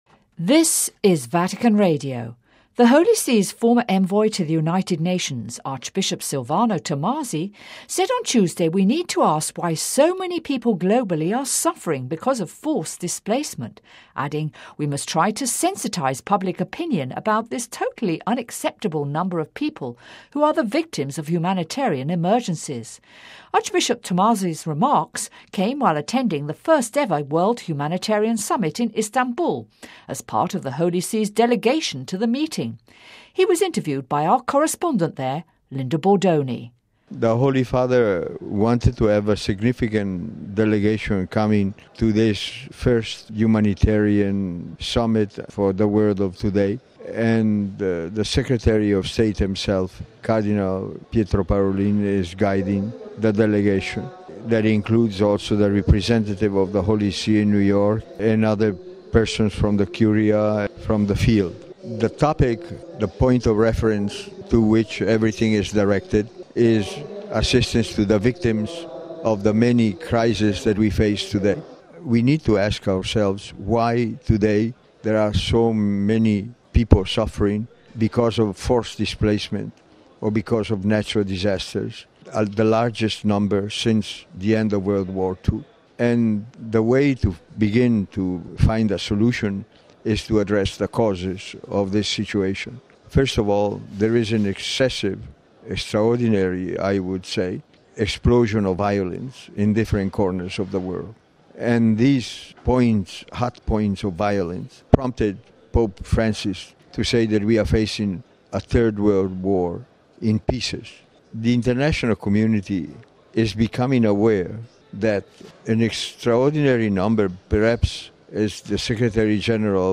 Listen to the interview with Archbishop Silvano Tomasi: